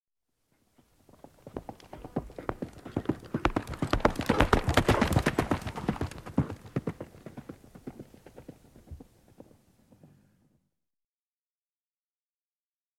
دانلود صدای اسب 20 از ساعد نیوز با لینک مستقیم و کیفیت بالا
جلوه های صوتی